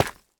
Minecraft Version Minecraft Version latest Latest Release | Latest Snapshot latest / assets / minecraft / sounds / block / tuff_bricks / step4.ogg Compare With Compare With Latest Release | Latest Snapshot
step4.ogg